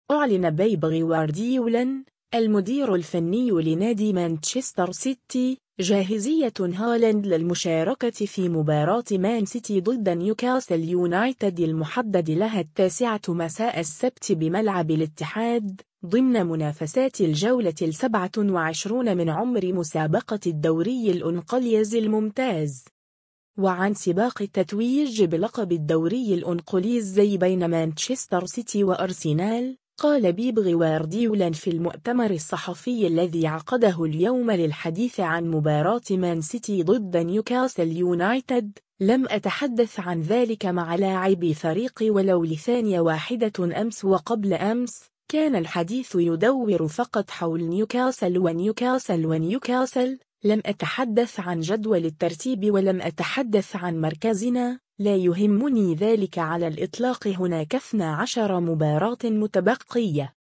و عن سباق التتويج بلقب الدوري الإنقليزي بين مانشستر سيتي و أرسنال، قال بيب غوارديولا في المؤتمر الصحفي الذي عقده اليوم للحديث عن مباراة مان سيتي ضد نيوكاسل يونايتد ، “لم أتحدث عن ذلك مع لاعبي فريقي و لو لثانية واحدة أمس و قبل أمس، كان الحديث يدور فقط حول نيوكاسل و نيوكاسل و نيوكاسل، لم أتحدث عن جدول الترتيب و لم أتحدث عن مركزنا، لا يهمني ذلك على الإطلاق هناك 12 مباراة متبقية”.